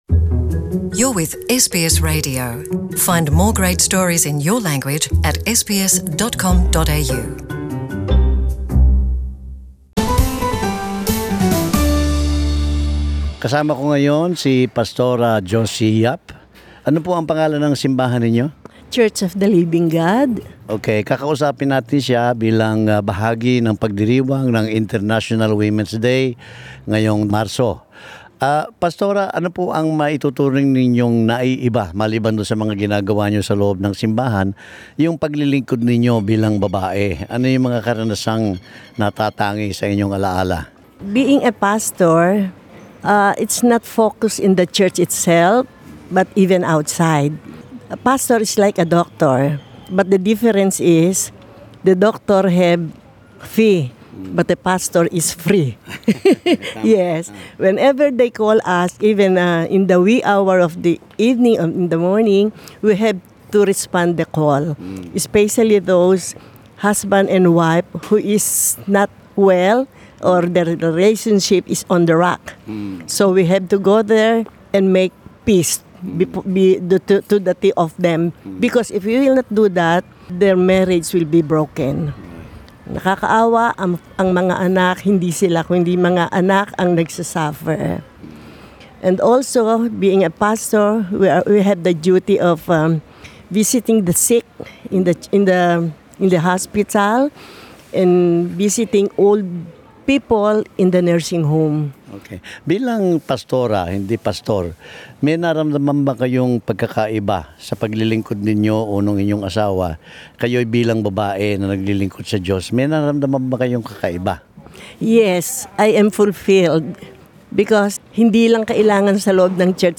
Bilang bahagi ng pardiriwang ng International Women's Day, kinausap namin ang isang babaeng pastor ng isang Kristyanong simbahan.